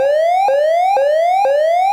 Alarm